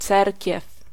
Ääntäminen
France: IPA: [e.ɡliz]